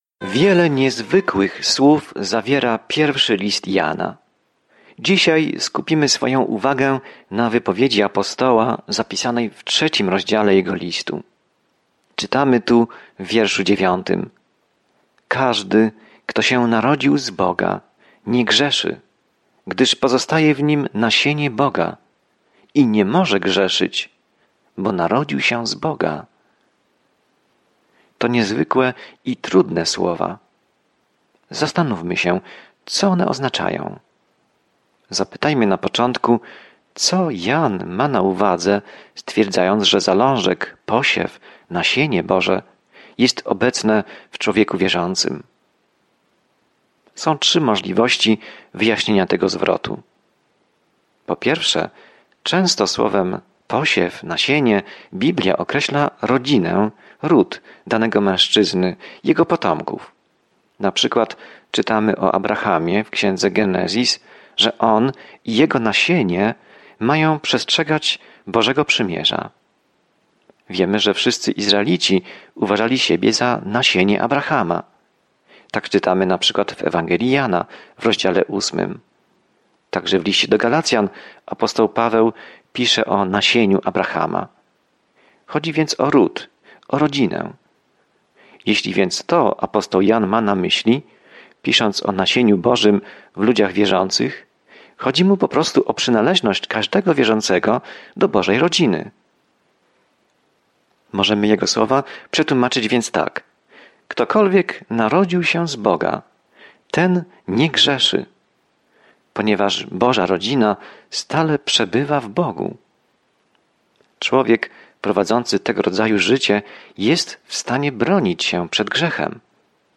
Codziennie podróżuj po 1 Liście Jana, słuchając studium audio i czytając wybrane wersety ze słowa Bożego.